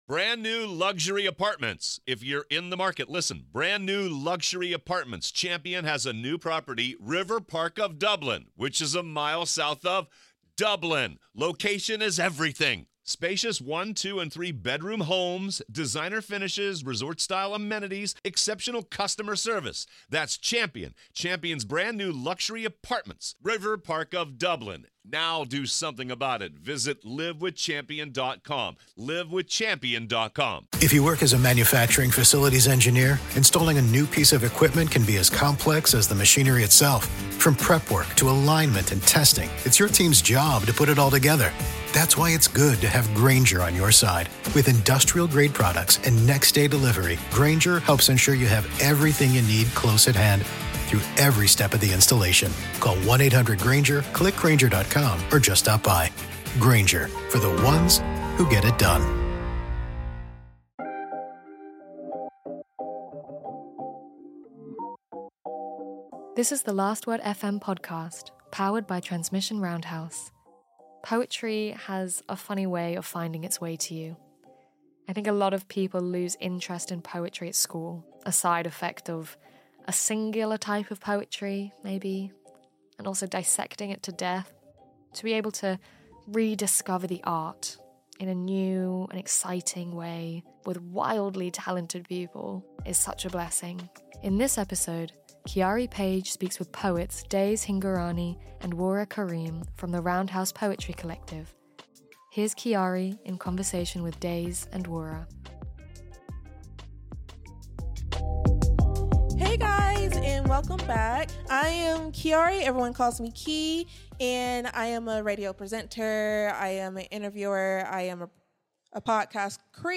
They discuss being interdisciplinary artists, working with difficult emotions, the experience of being in a collective and the act of calling yourself a poet. The Last Word FM is an annual live broadcast powered by Transmission Roundhouse with talks, interviews and panels with a number of incredible artists whose work was featured as part of The Last Word Festival.